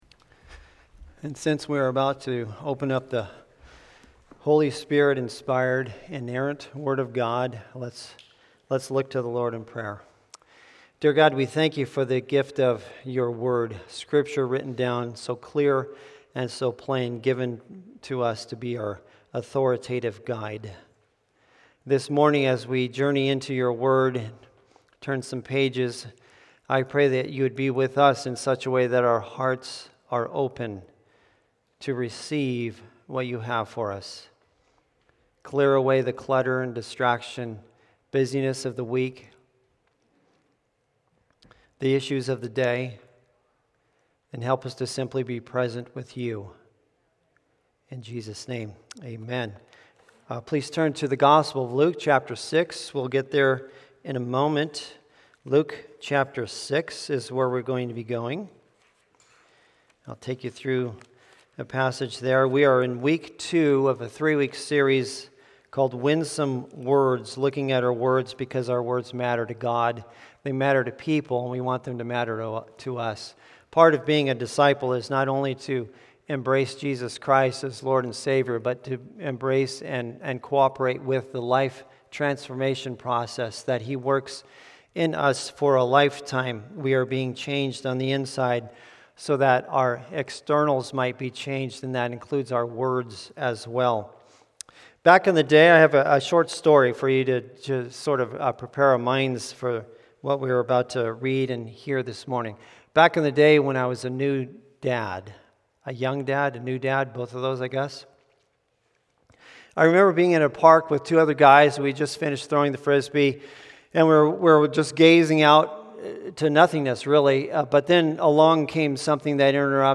Sermons | Hope Community Church